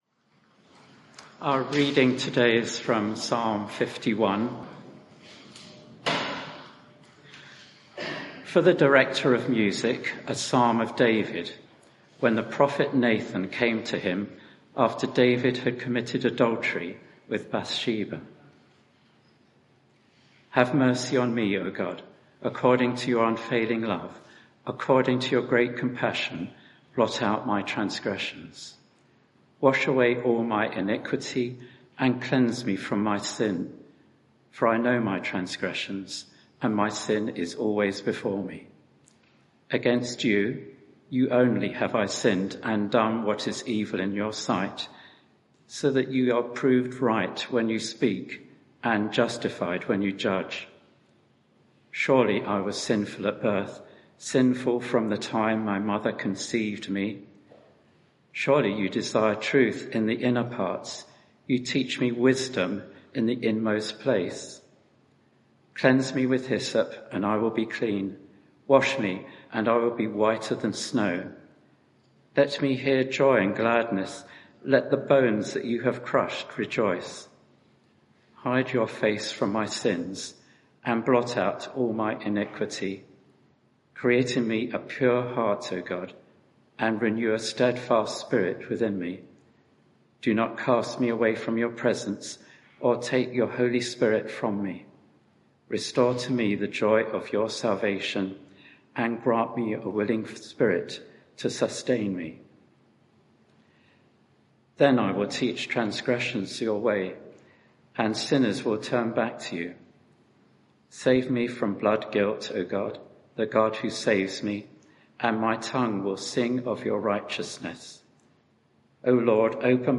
Media for 11am Service on Sun 13th Aug 2023 11:00 Speaker
Sermon (audio)